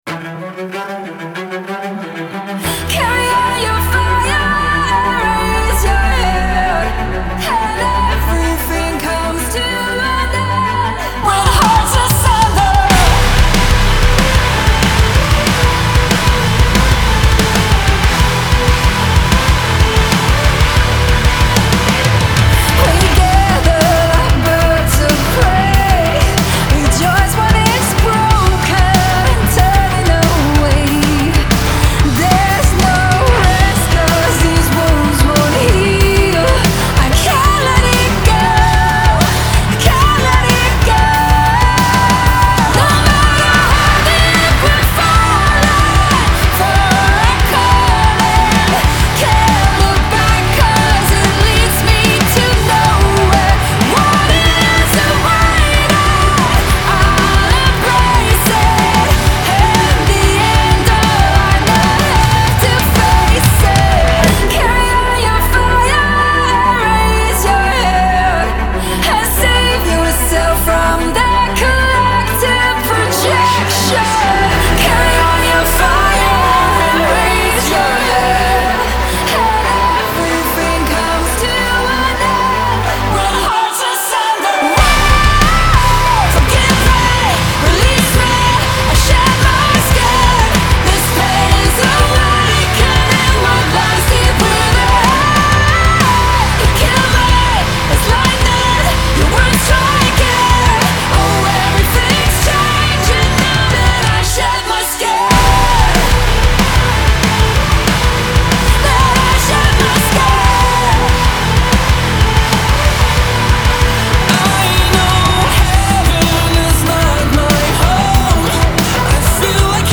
Genre : Hard Rock